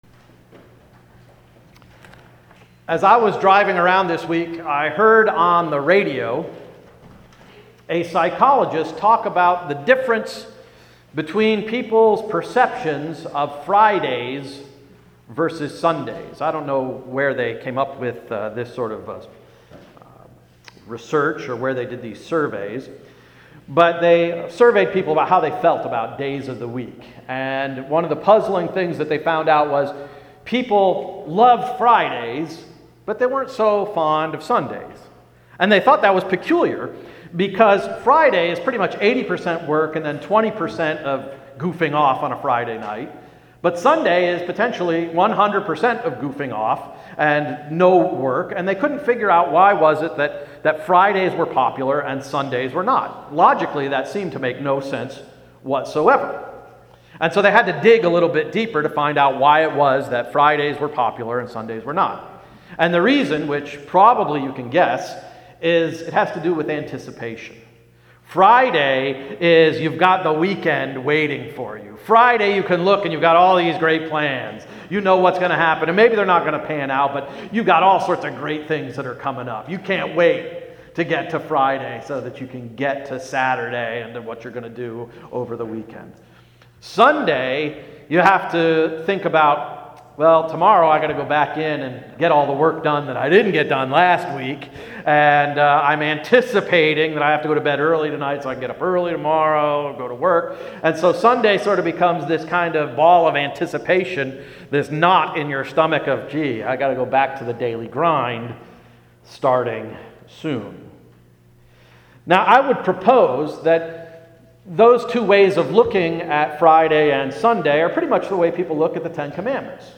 May 8, 2016 Sermon–“Ten Tasks”